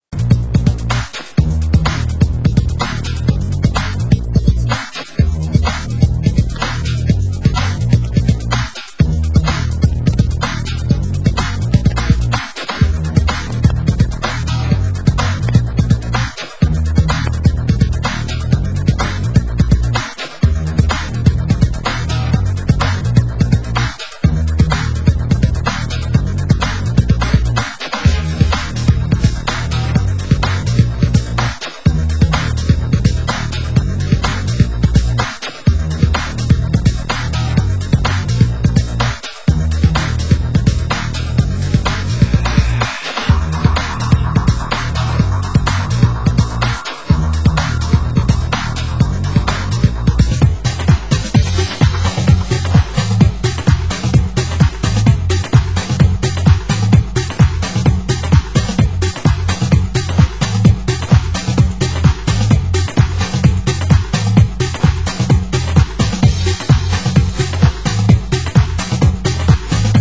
Format: Vinyl 12 Inch
Genre: Tech House